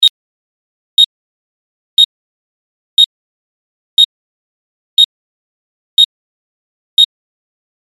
جلوه های صوتی
دانلود صدای ساعت 3 از ساعد نیوز با لینک مستقیم و کیفیت بالا